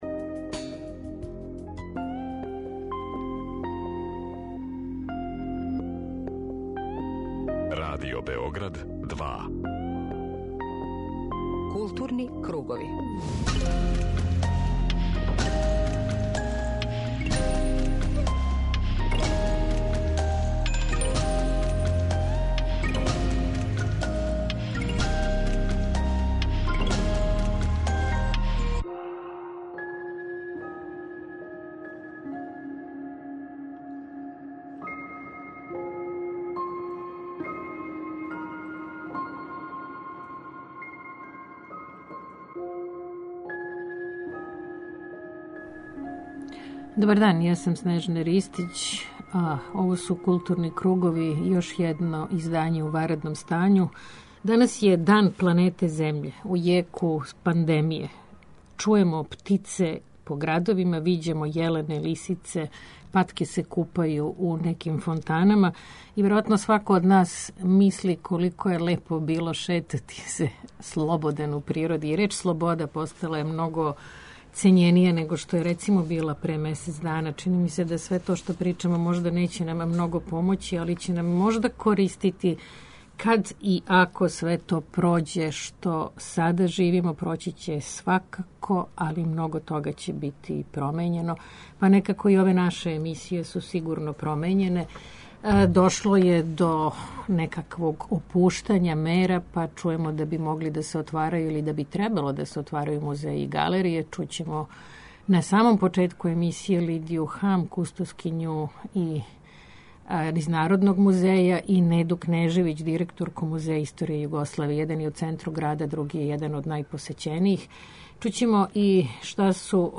О тренутку у којем смо се нашли, ексклузивно из Љубљане за Културне кругове говори књижевница, социолошкиња и професорка хуманистичких наука Светлана Слапшак, специјалисткиња за античке студије, ауторка више од 40 књига и преко 1.000 чланака и научних радова.